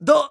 dhan_voice_delay.mp3